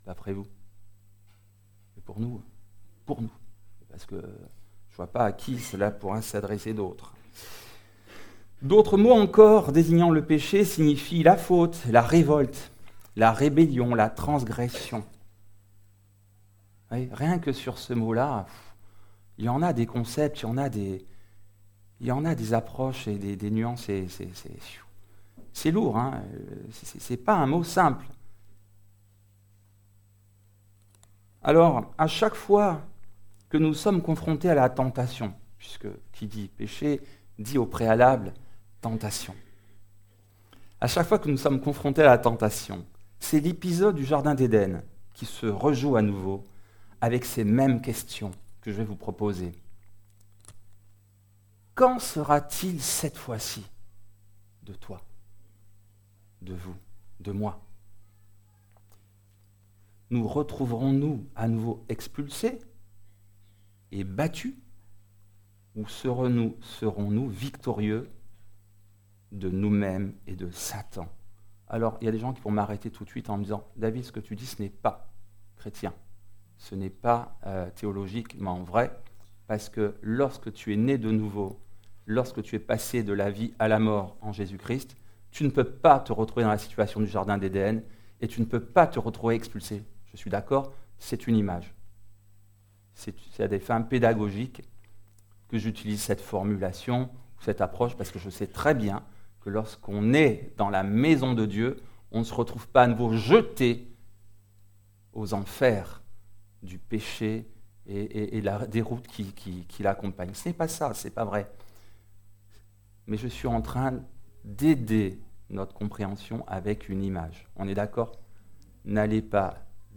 Type De Service: Culte